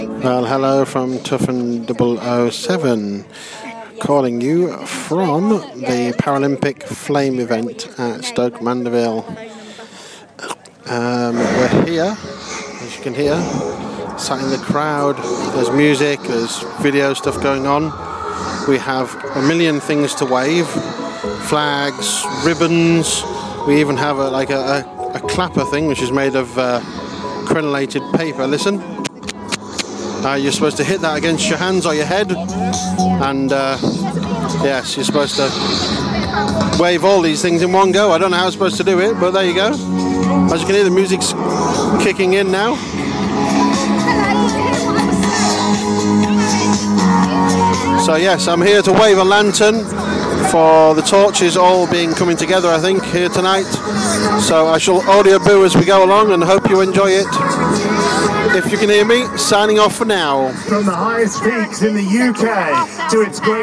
Paralympic Flame event part 1 - London 2012 Paralympic torch lighting